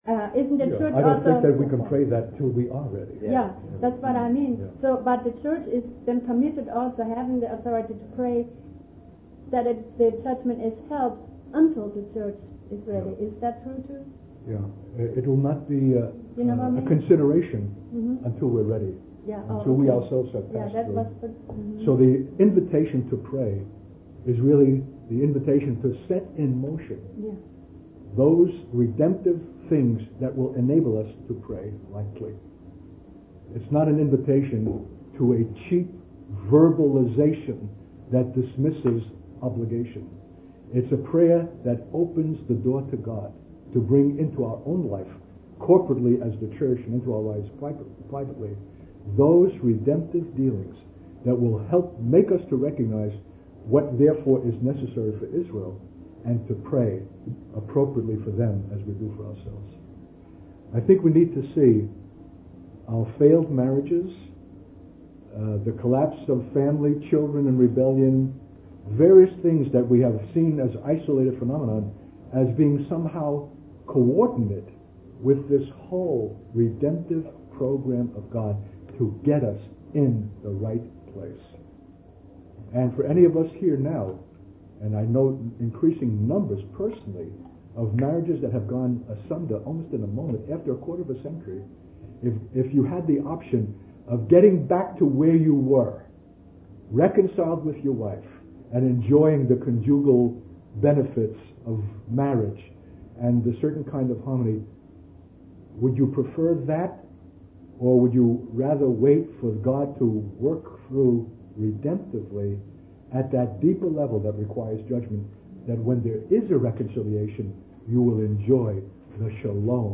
In this sermon, the speaker describes a man who dedicated his life to seeking God every morning.